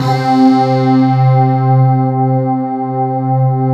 PAD K-20009R.wav